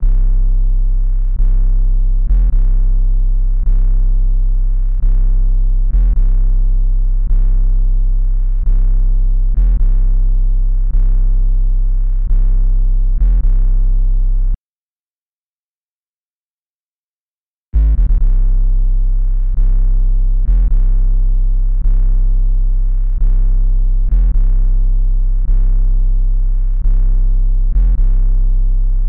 特拉维斯斯科特Kick和808的热滑行
描述：鸡皮疙瘩式的踢腿和808
标签： 132 bpm Trap Loops Drum Loops 4.89 MB wav Key : D
声道立体声